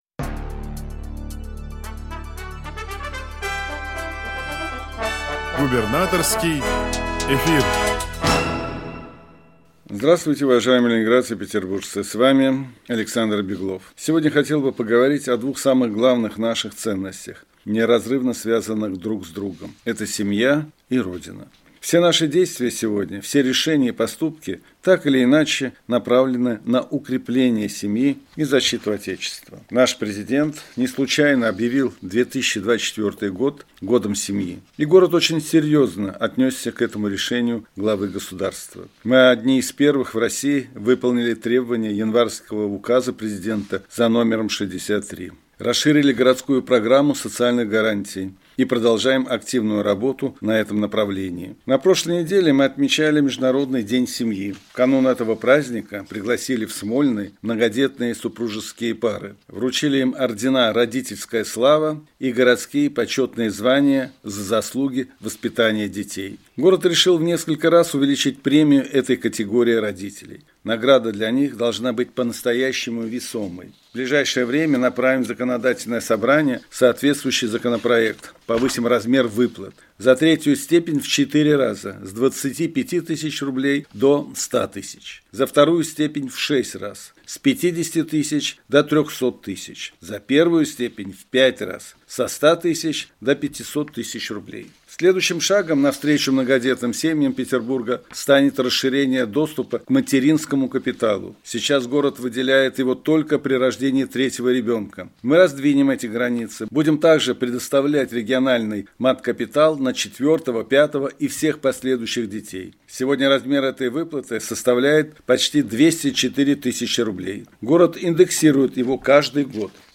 Радиообращение – 20 мая 2024 года